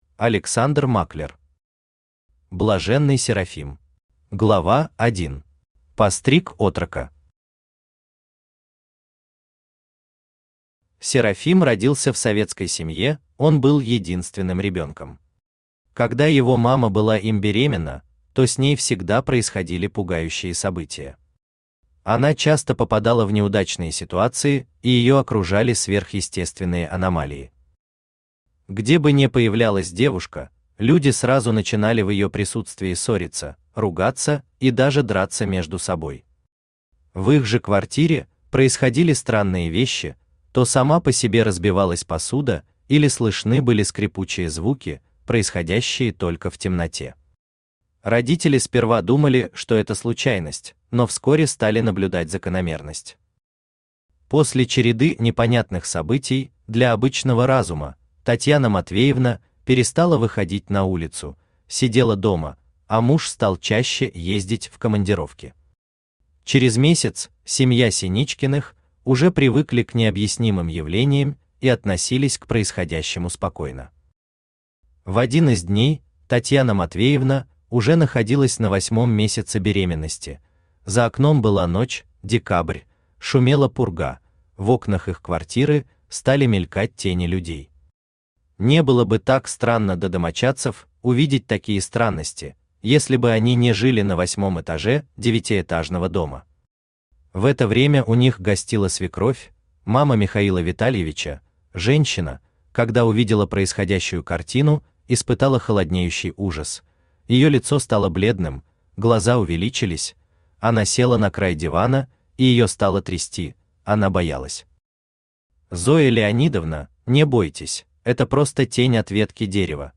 Аудиокнига Блаженный Серафим | Библиотека аудиокниг
Читает аудиокнигу Авточтец ЛитРес